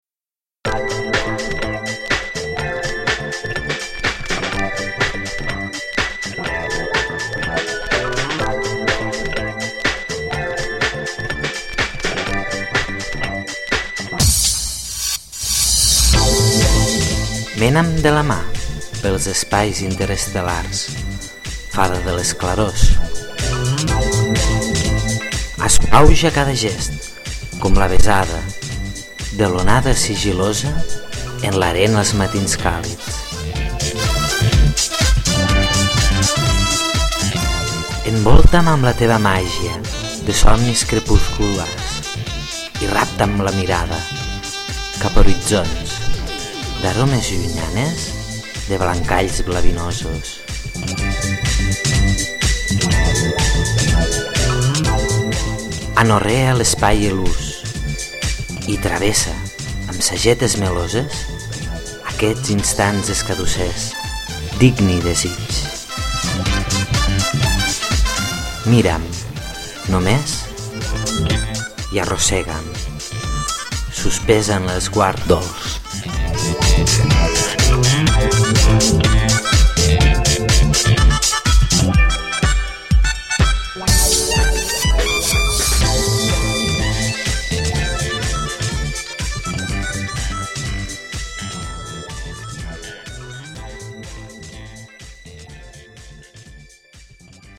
La música del podcast ve de la mà del grup «Staiff featuring Klub»: funky del bo!